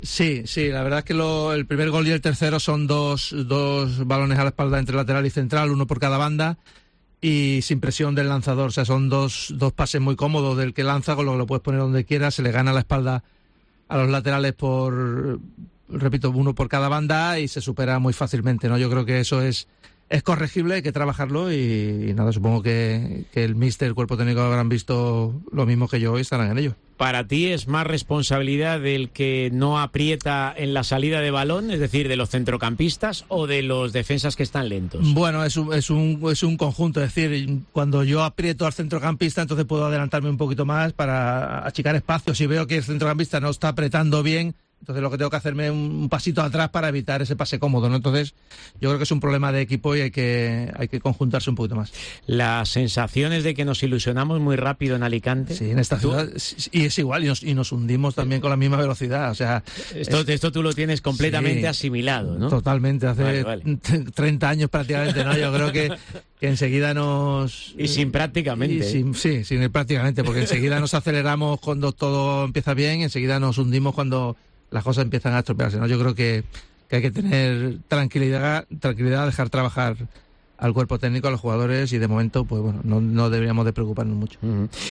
La Tertulia XXL de Deportes COPE ha contado este martes con una leyenda del herculanismo como Eduardo Rodríguez.